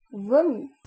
Ääntäminen
IPA : /ˈpjuːk/